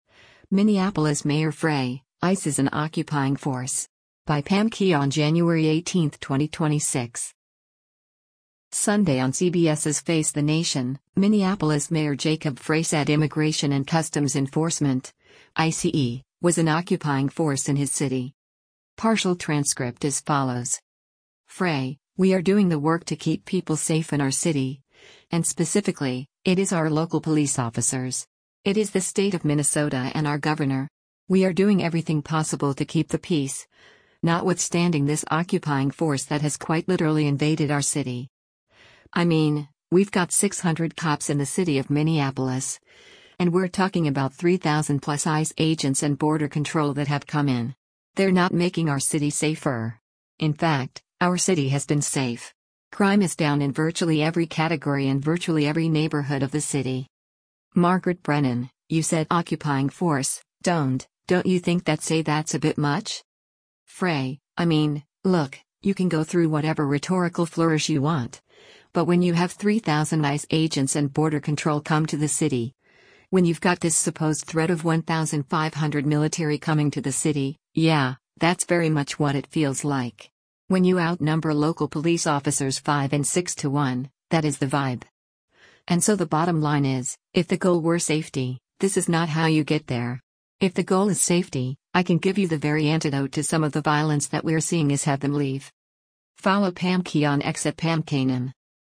Sunday on CBS’s “Face the Nation,” Minneapolis Mayor Jacob Frey said Immigration and Customs Enforcement (ICE) was an “occupying force” in his city.